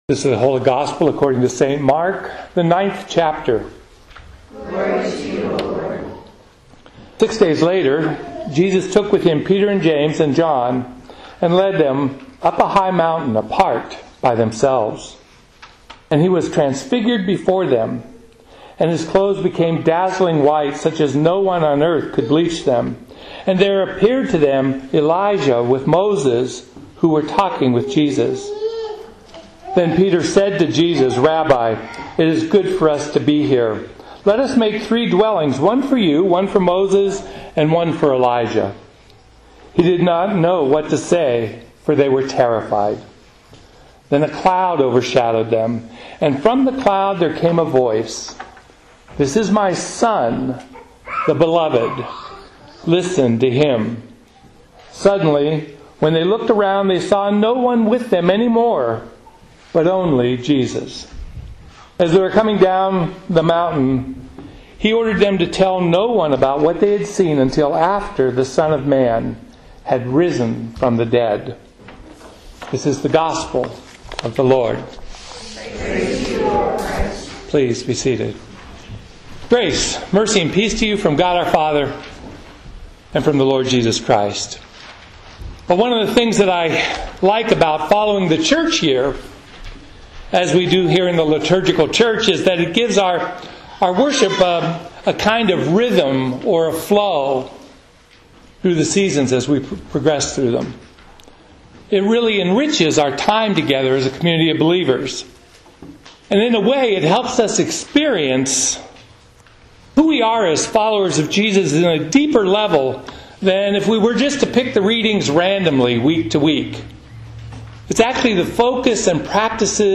God, Jesus, Transfiguration, Epiphany, Lent, Service.